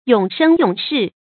永生永世 yǒng shēng yǒng shì 成语解释 永远。